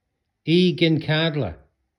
4. игенкадла